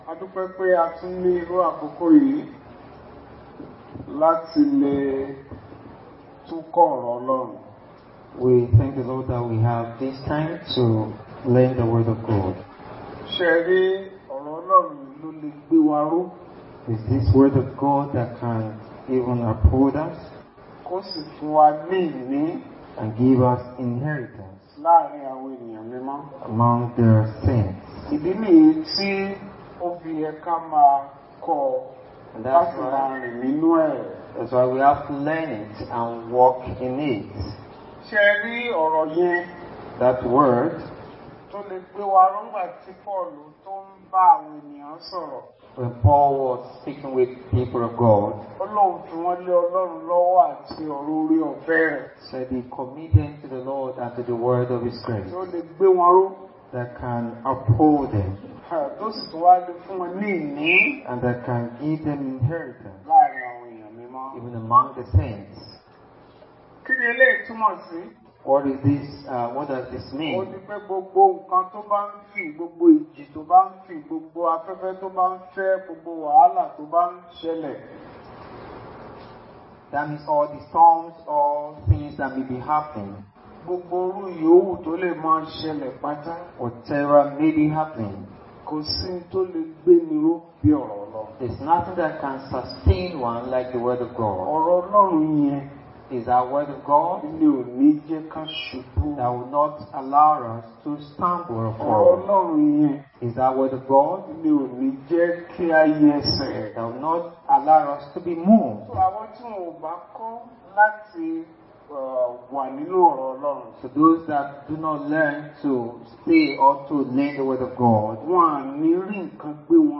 Bible Class Passage: Galatians 2:11-16, Galatians 6:12-14